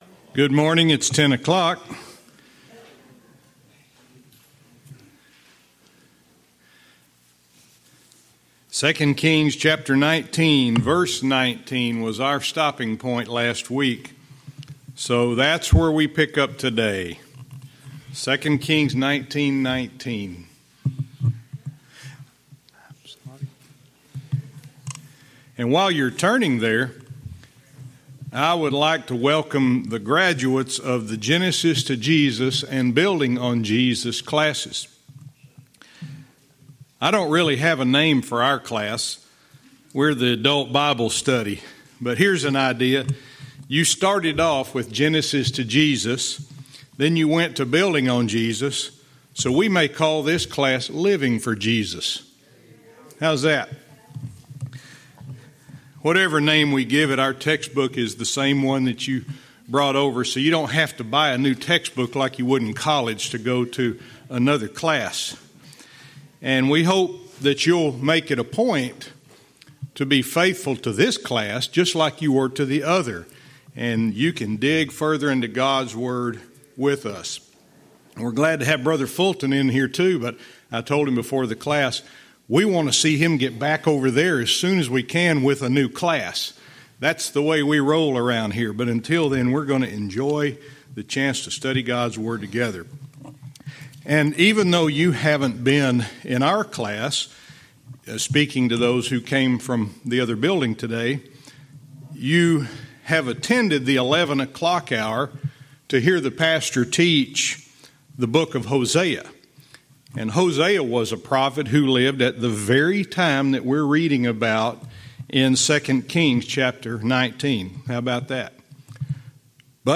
Verse by verse teaching - 2 Kings 19:19-21